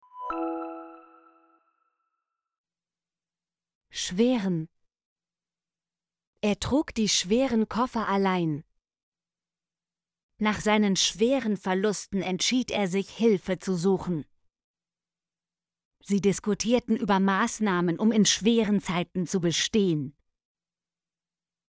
schweren - Example Sentences & Pronunciation, German Frequency List